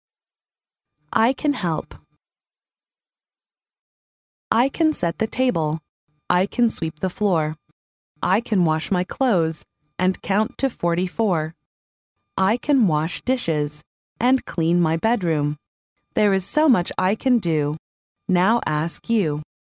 幼儿英语童谣朗读 第12期:我是小帮手 听力文件下载—在线英语听力室